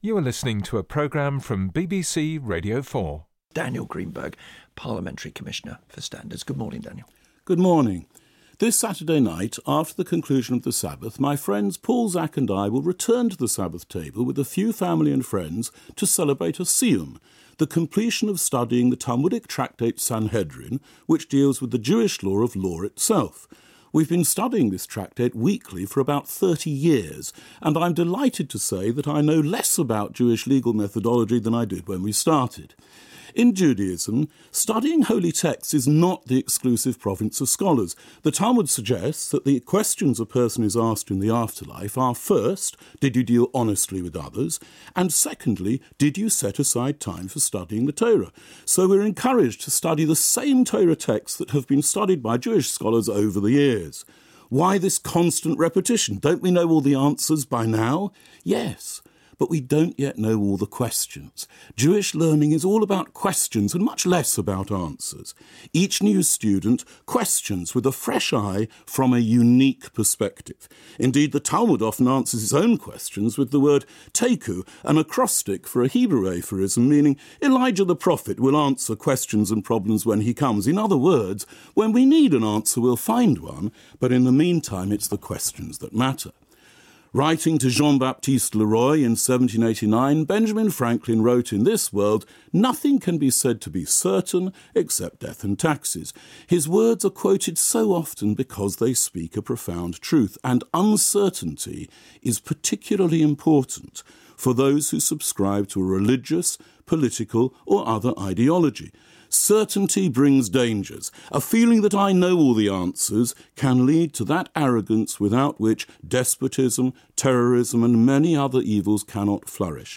I gave a talk on “Questions & Answers”